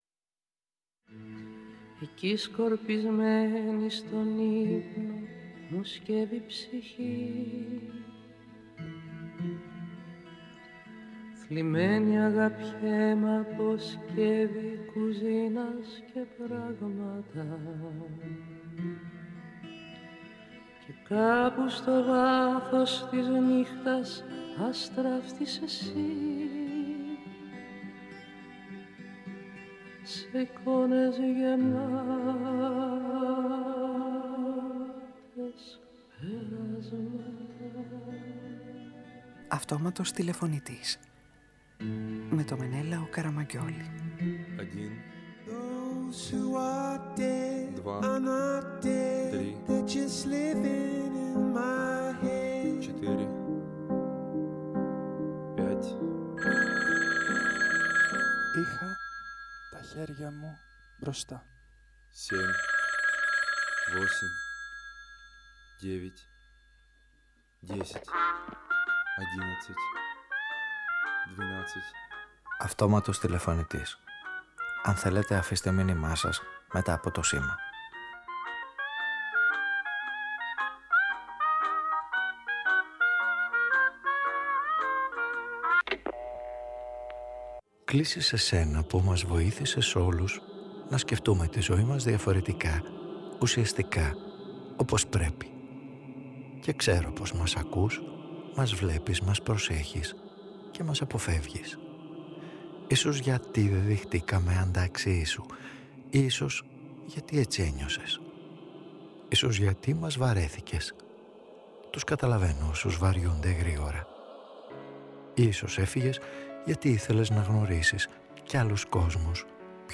Παραγωγή-Παρουσίαση: Μενέλαος Καραμαγγιώλης